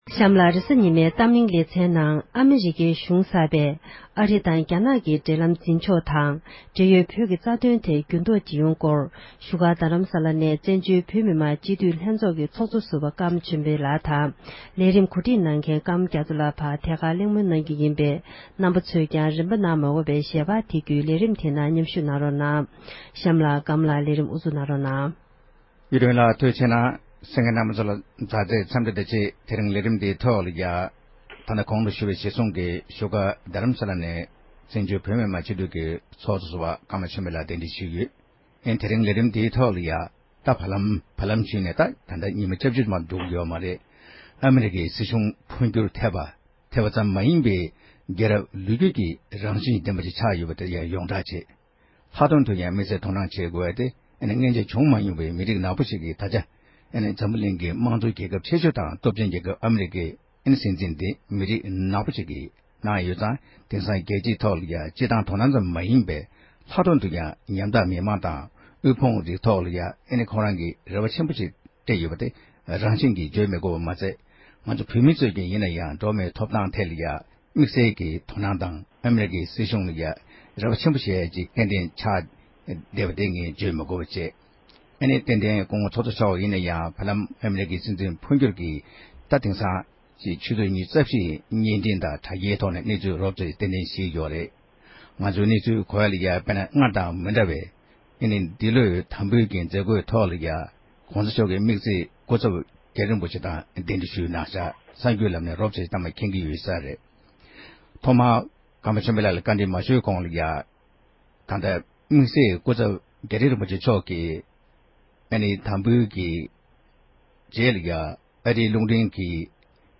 དབར་གླེང་མོལ་ཞུས་པར་གསན་རོགས་ཞུ༎